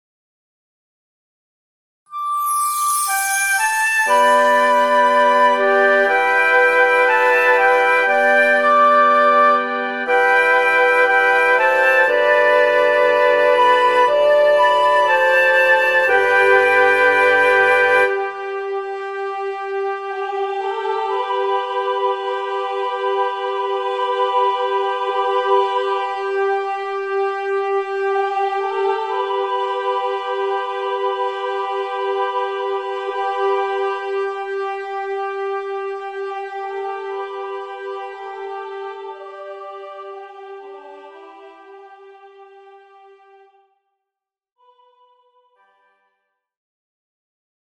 MP3 Sample:
SATB Choral/Piano Arrangement with MP3 recording